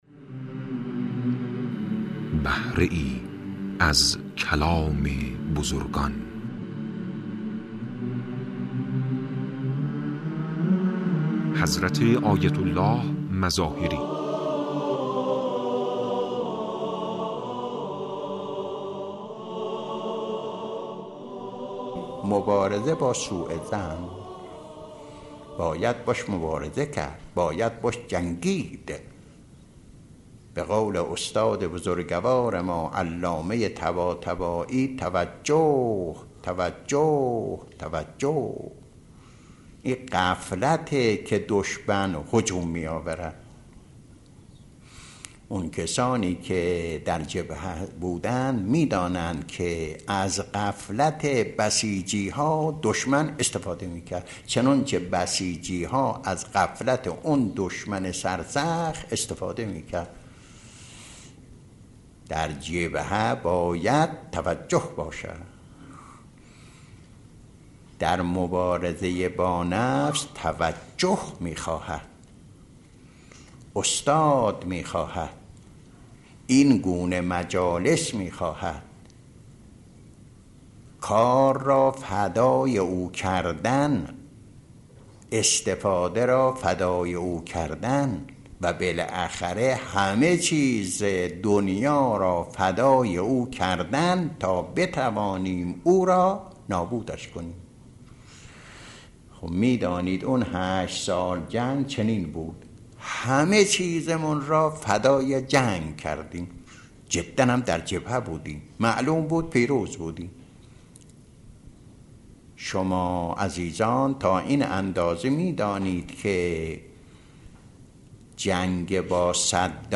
مواعظ گوناگون و مطالب و نکاتی که در قالب صوت هستند و مختصر و مفید می‌باشند و پند و اندرز می‌دهند، در این بخش مطرح می‌شود.